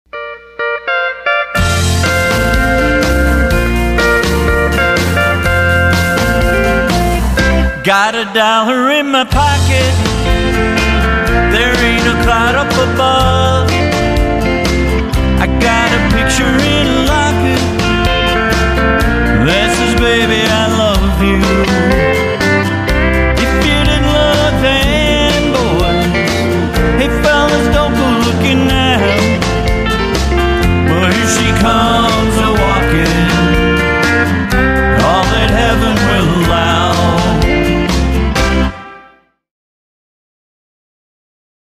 HonkyTonk / Outlaw / Classic Country
Band Demo